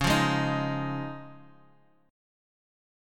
Listen to C#add9 strummed